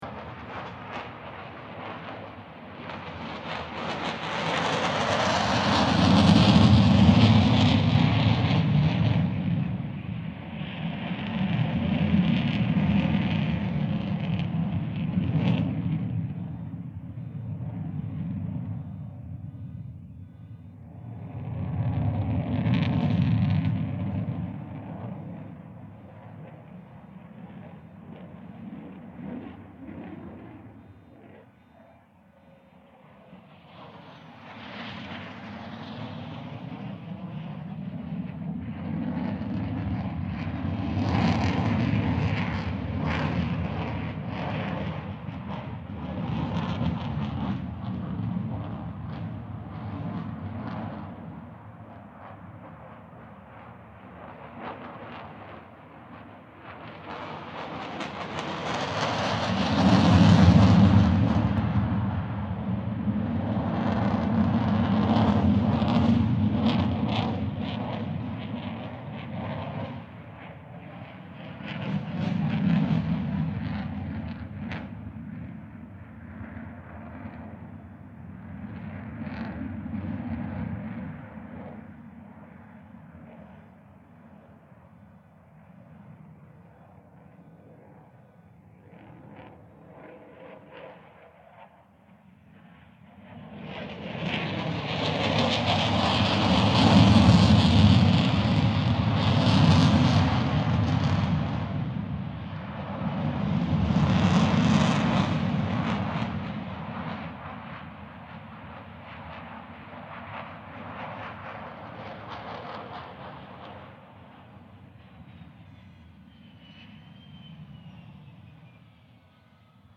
Le Bourget Air Meeting – 19, 20 & 21 June 2009
Junckers Ju-52-3m